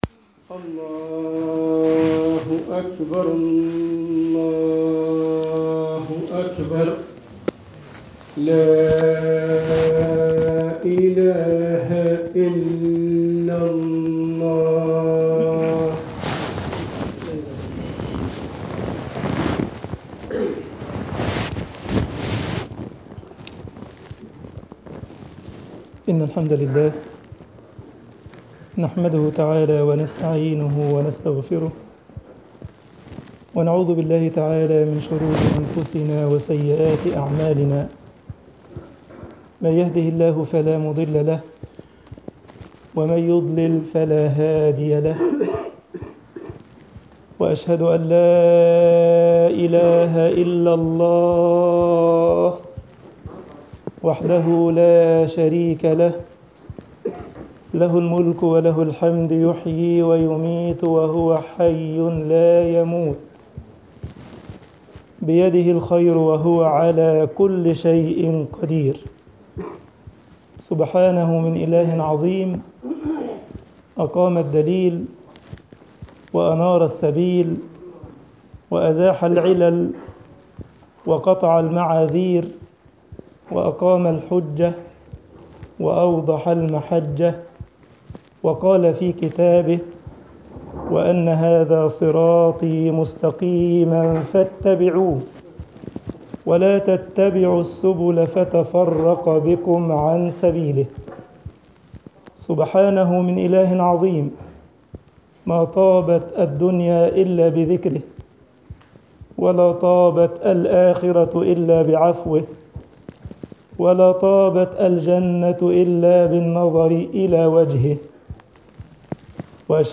Freitagsgebet_al esmat min fetnat al-ilm11.mp3